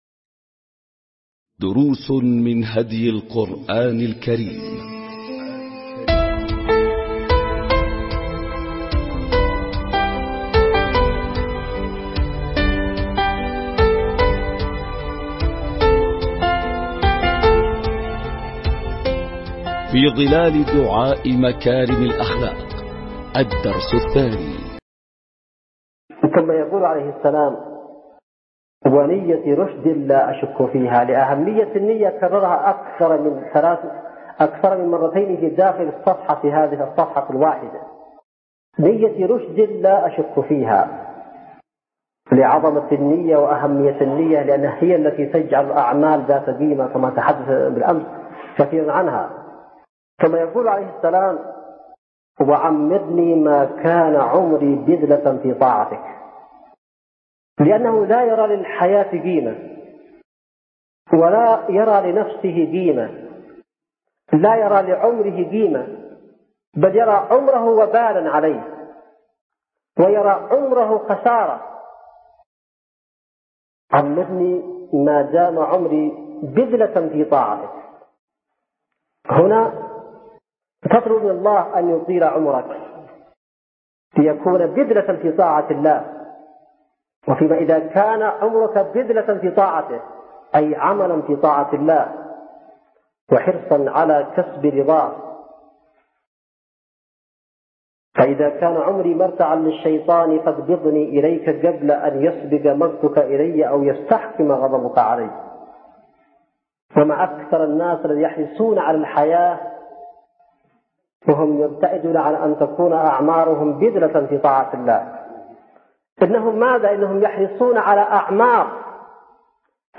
🟢دروس من هدي القرآن الكريم 🔹في ظلال دعاء مكارم الأخلاق – الدرس الثاني🔹 ملزمة الأسبوع | اليوم السادس ألقاها السيد / حسين بدرالدين الحوثي بتاريخ 2/2/2002م | اليمن – صعدة | مؤسسة الشهيد زيد علي مصلح